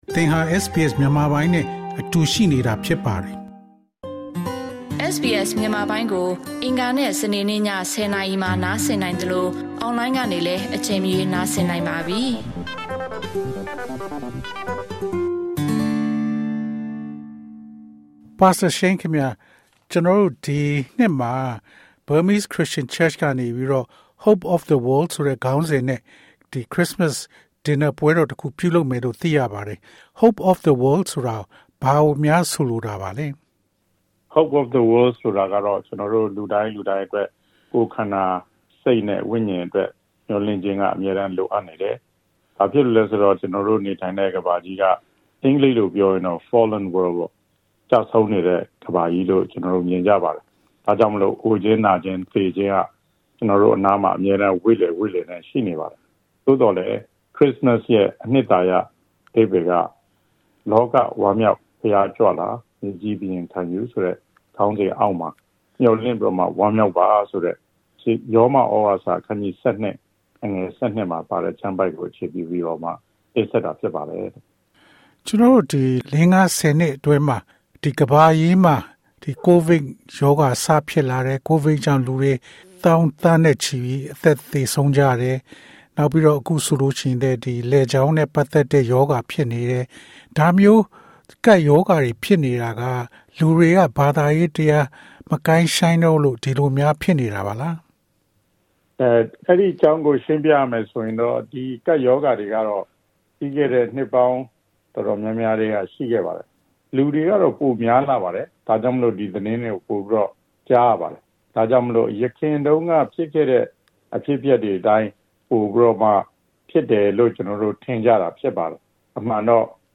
မေးမြန်းထားခန်း။